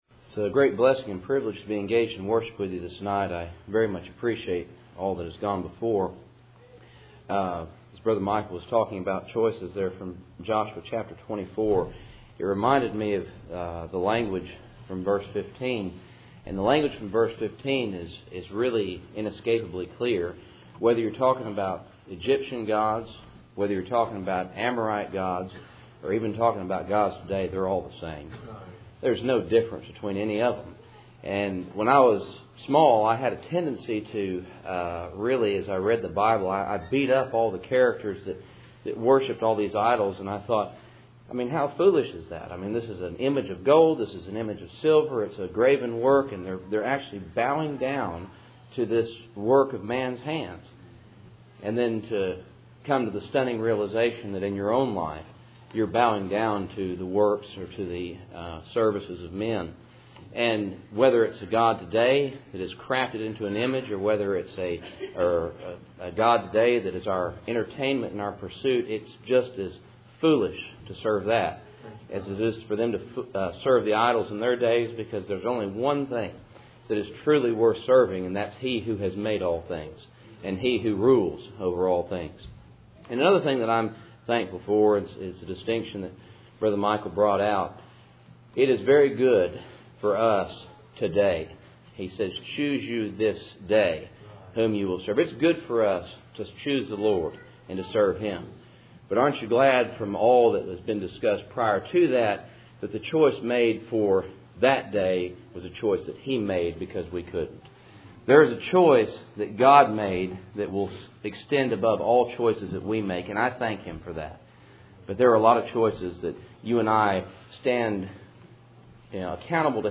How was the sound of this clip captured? Cool Springs PBC Sunday Evening %todo_render% « How Do You Choose?